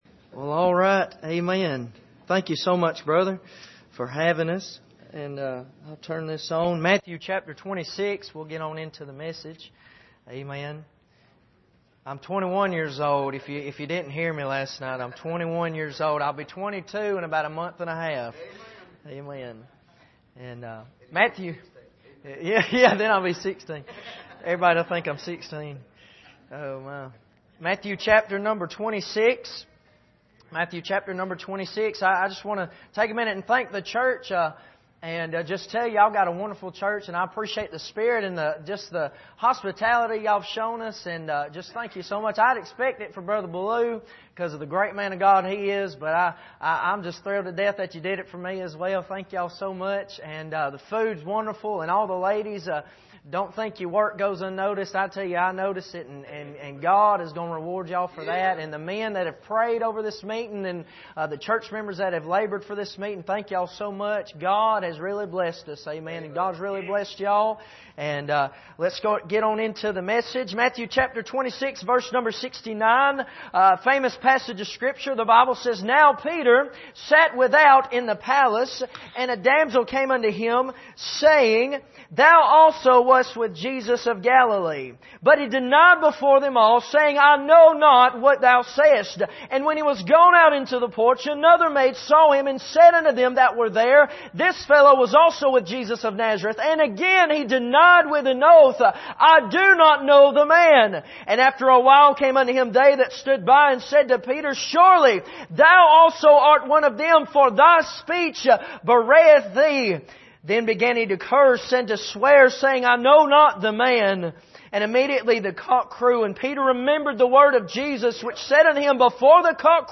2016 Missions Conference Passage: Matthew 26:69-75 Service: Missions Conference How Did I Get Here?